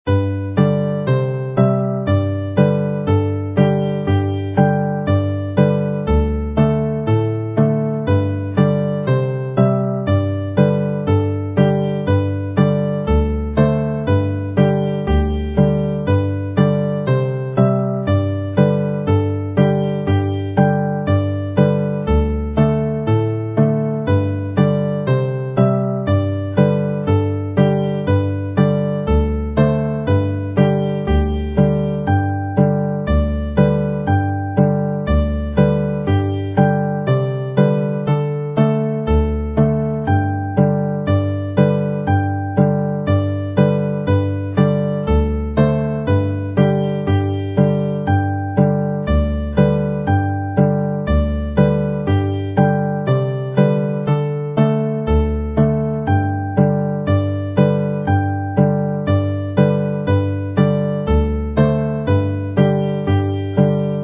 mp3 alaw + cordiau